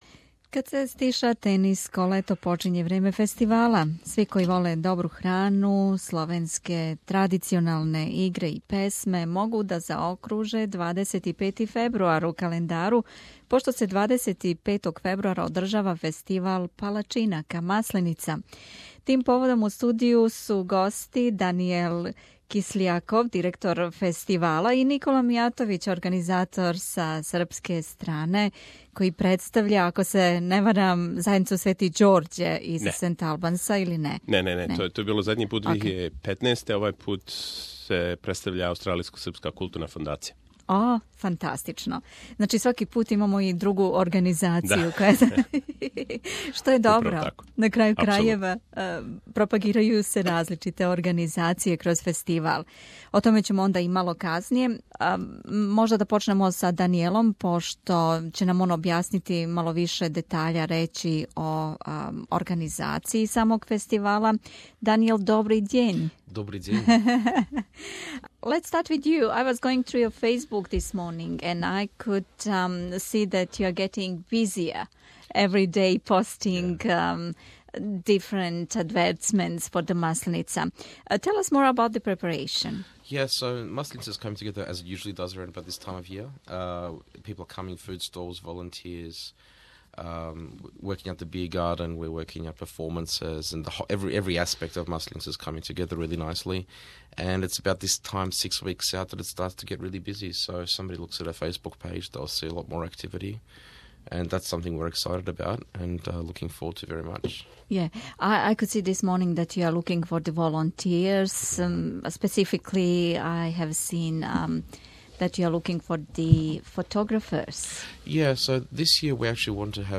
Сви који воле добру храну, словенске традиционалне игре и песме могу да заокруже 25. фебруар у календару, пошто се 25. фебруара одржава фестивал палачинака Масленица. Тим поводом у студију су били гости